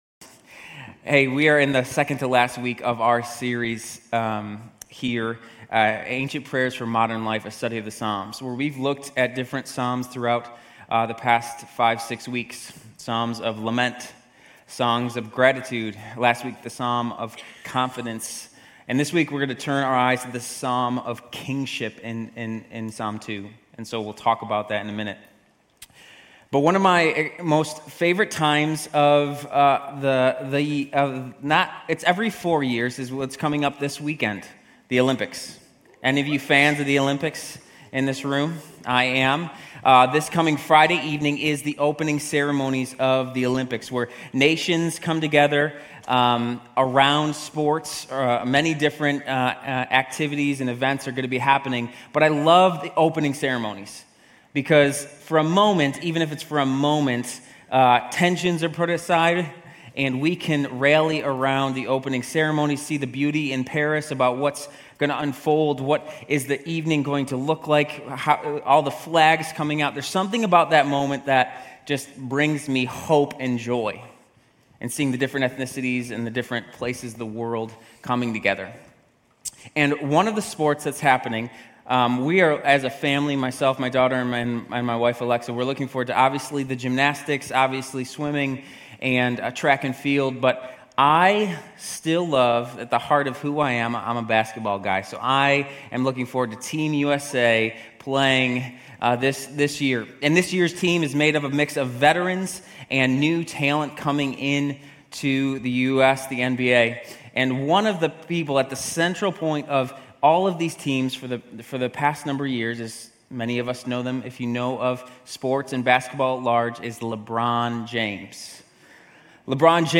Grace Community Church University Blvd Campus Sermons Psalm 2 - Kingship Jul 21 2024 | 00:41:31 1x 00:00 / 00:41:31 Subscribe Share RSS Feed Share Link Embed